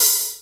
OpenHat [UziGang].wav